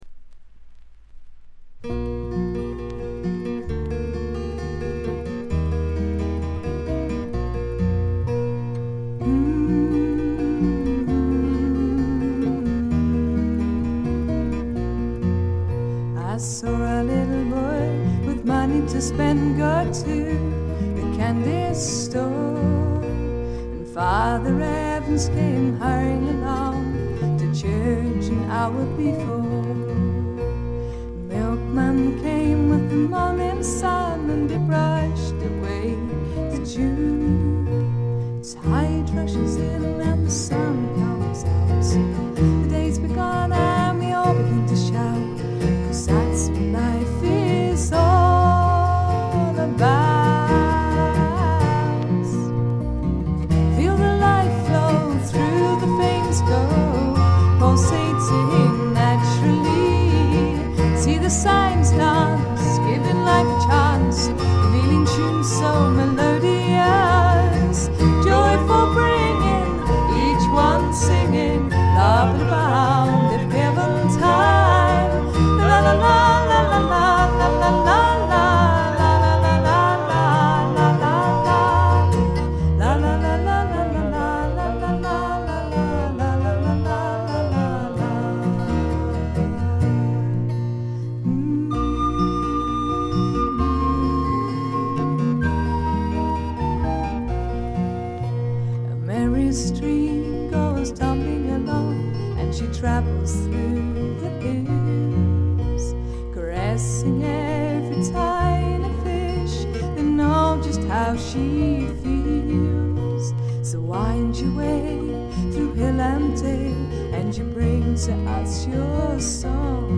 試聴曲は現品からの取り込み音源です。
recorder, bongoes
electric bass
cello